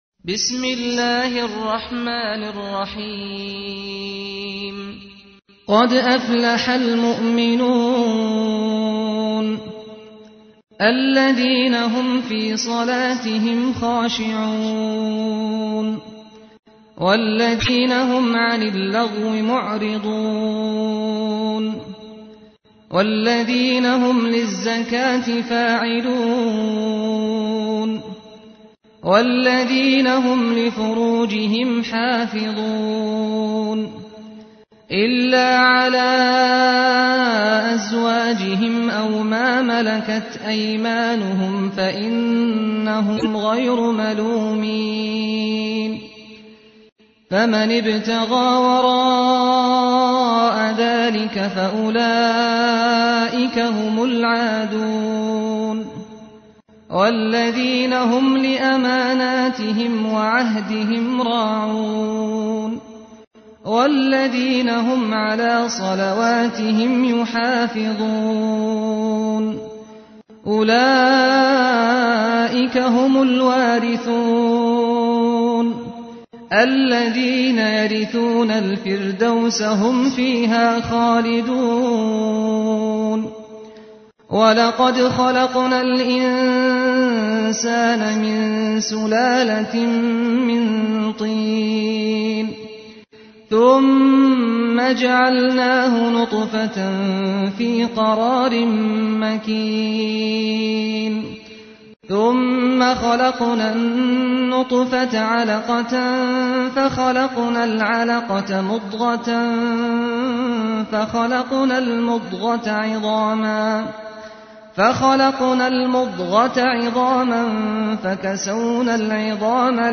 تحميل : 23. سورة المؤمنون / القارئ سعد الغامدي / القرآن الكريم / موقع يا حسين